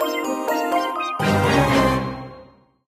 gift_shop_load_01.ogg